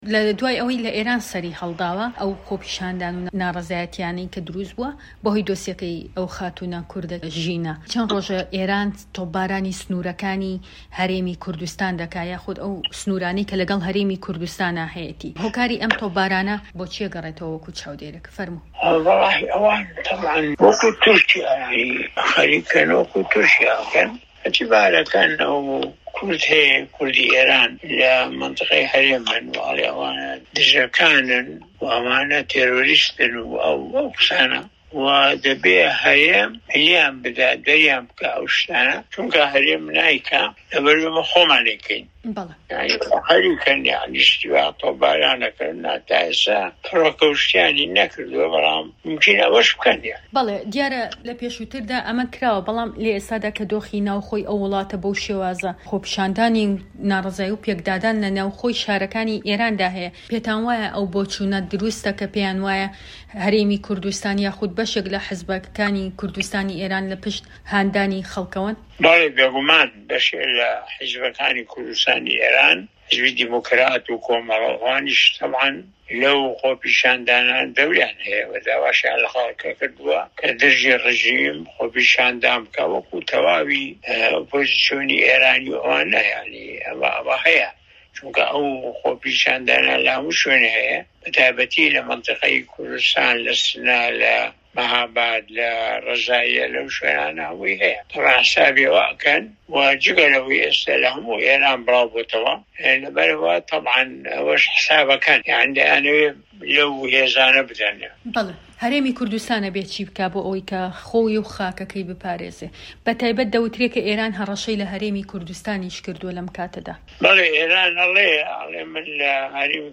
دەقی وتووێژەکەی دکتۆر مەحمود عوسمان